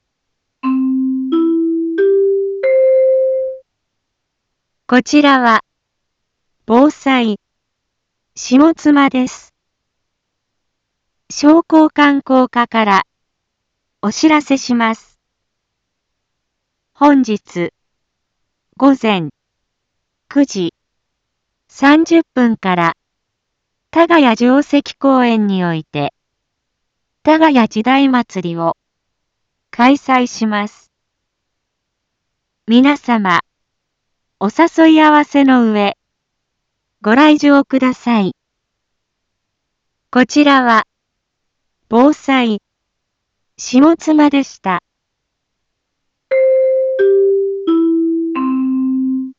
一般放送情報
Back Home 一般放送情報 音声放送 再生 一般放送情報 登録日時：2024-04-28 08:30:53 タイトル：多賀谷時代まつりのお知らせ インフォメーション：こちらは、ぼうさい、しもつまです。